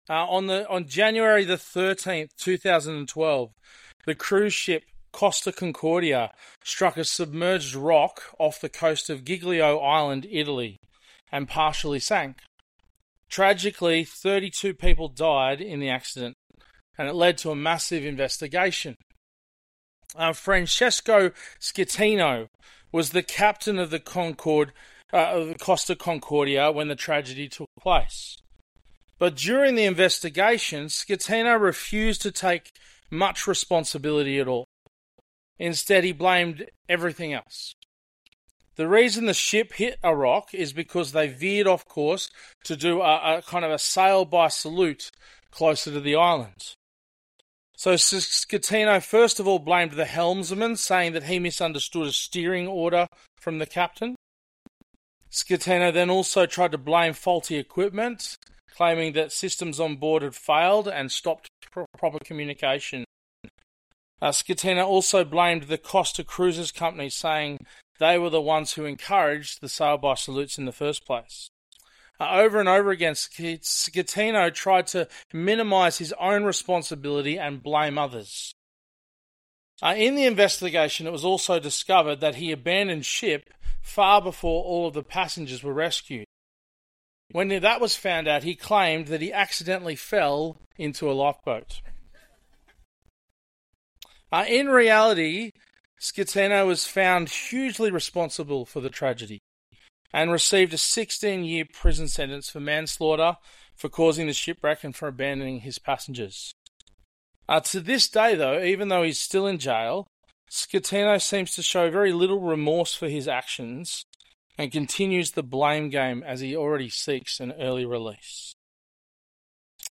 Bible Talks | Bairnsdale Baptist Church